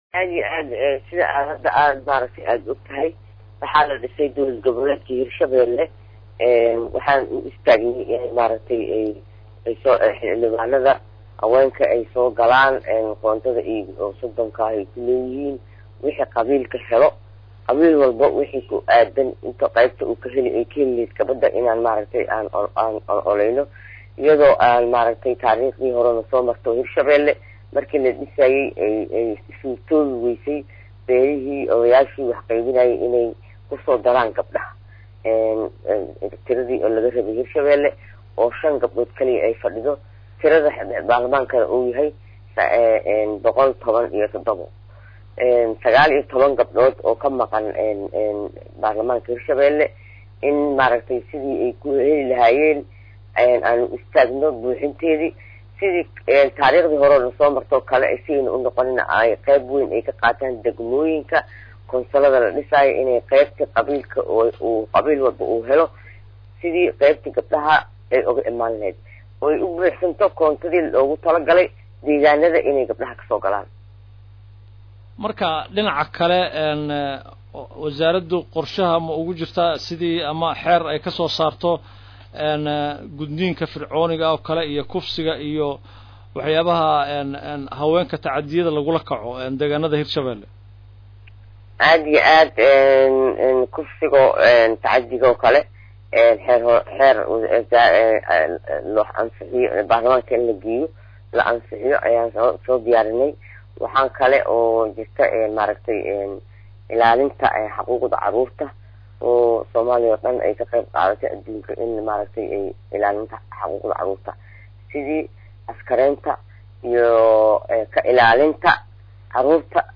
Wasiiru-dowlaha wasaaradda Haweenka iyo Xuquuqul Insaanka ee dowlad goboleedka Hirshabeelle Sacdiyo Maxamed Nuur “Buris” oo wareysi siiyay Radio Muqdisho ayaa ka hadashay qorshayaasha wasaaradda ee ku wajahan horumarinta haweenka iyo ilaalinta xuquuqda haweenka iyo caruurta.